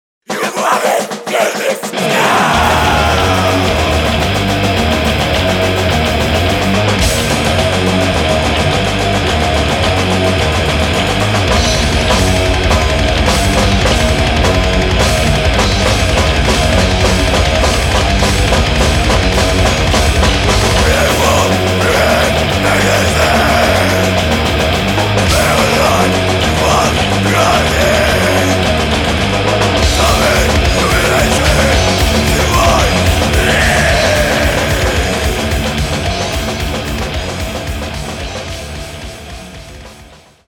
Furious Blacked Thrash Metal
Polish Black Thrash Attack!!!